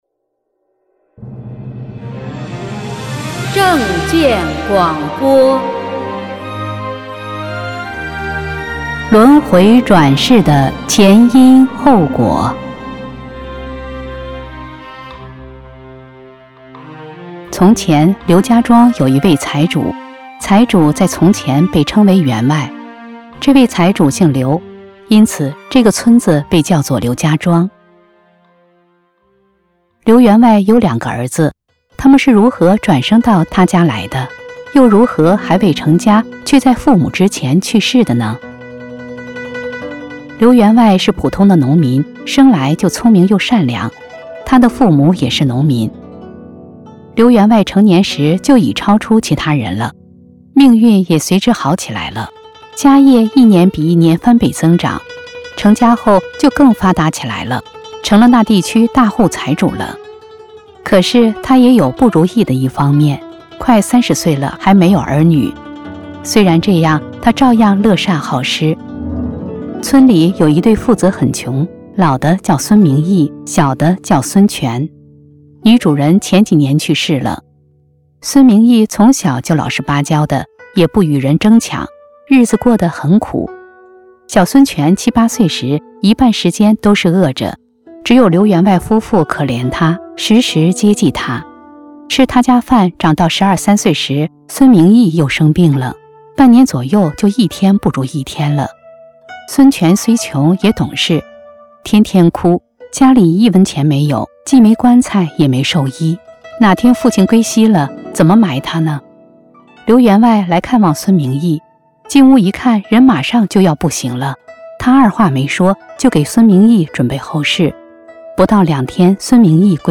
播音